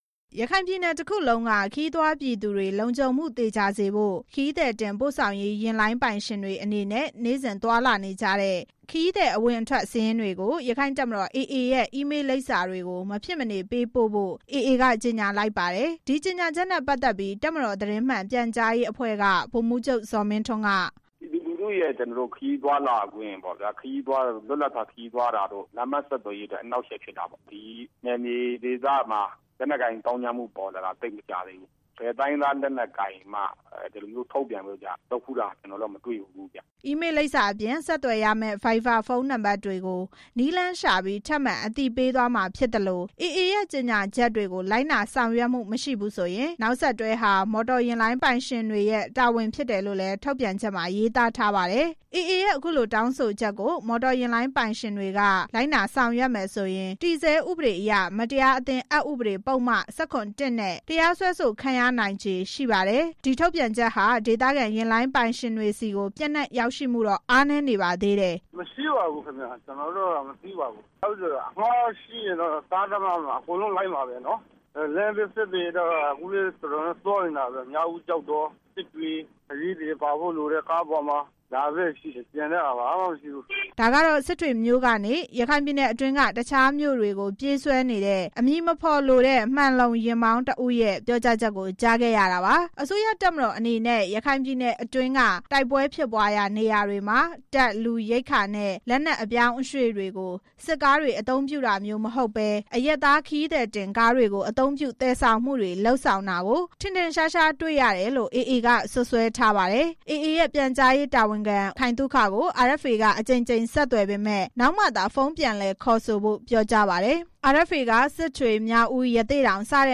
ဒါကတော့ စစ်တွေကနေ ရခိုင်ပြည်နယ်အတွင်းက တခြားမြို့တွေကို ပြေးဆွဲနေတဲ့ အမည်မဖော်လိုတဲ့ မှန်လုံယာဉ်မောင်း တစ်ဦးရဲ့ ပြောကြားချက်ကို ကြားခဲ့ရတာပါ။